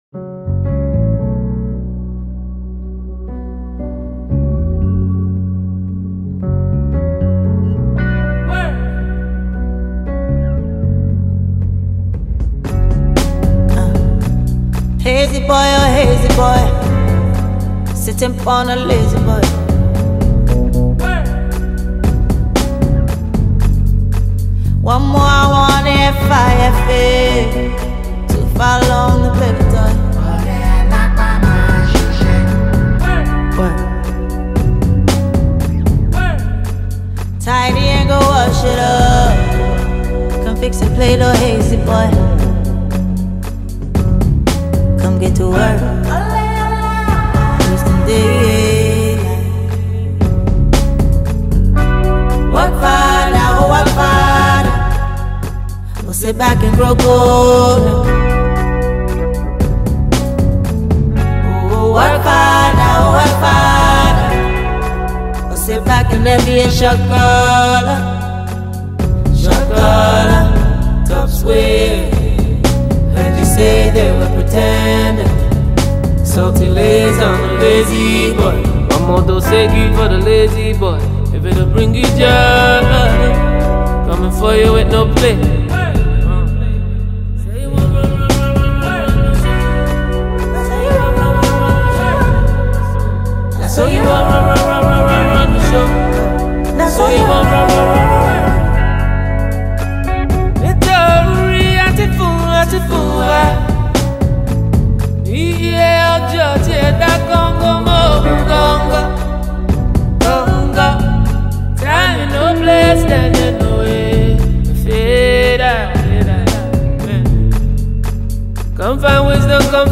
AfroBeats | AfroBeats songs
blends Afrobeat rhythms with hip-hop influences
soulful delivery